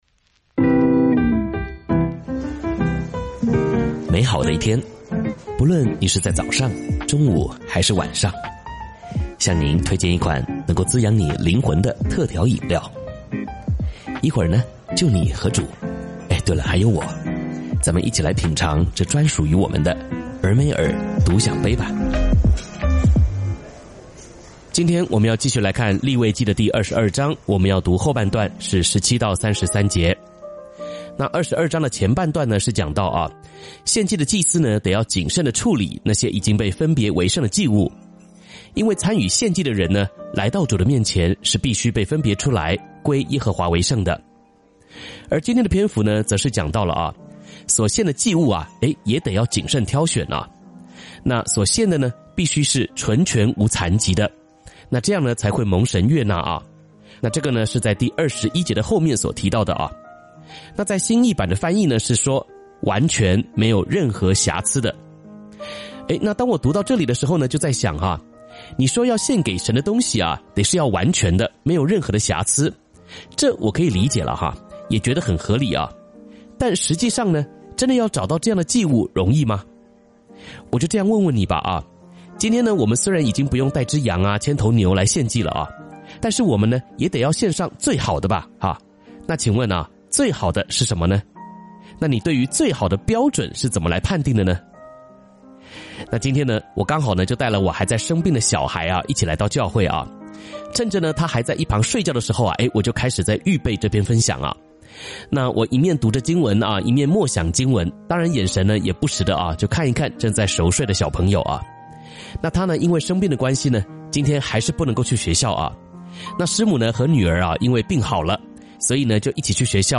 靈修分享：利22章17-33節「什麼是"最好的"？」